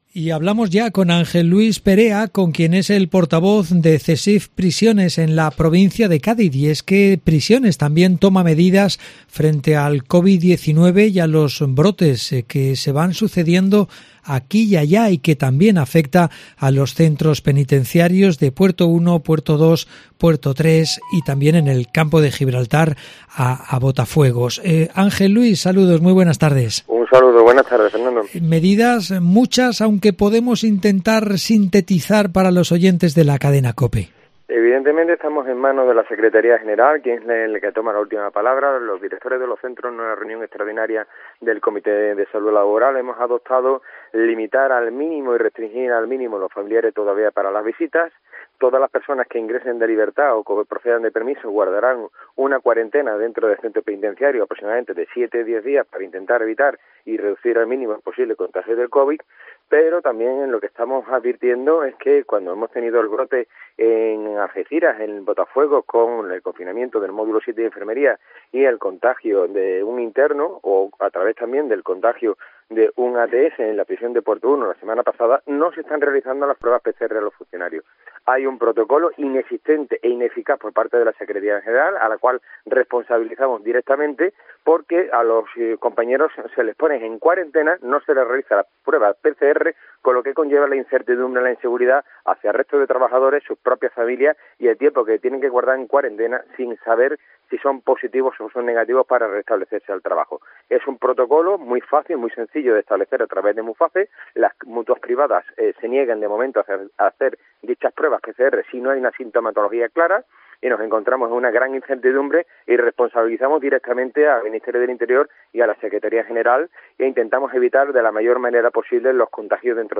En el informativo Mediodía Cope Provincia de Cádiz